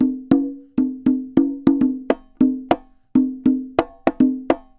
Congas_Samba 100_5.wav